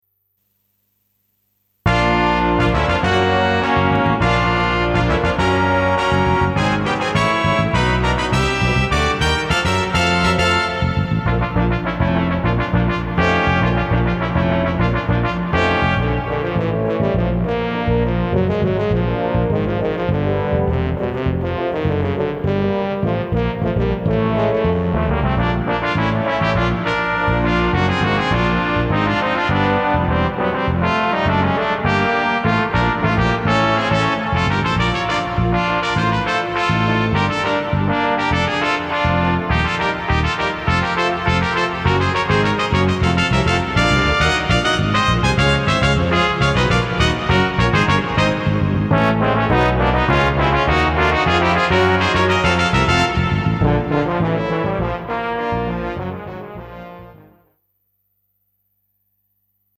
for Brass Octet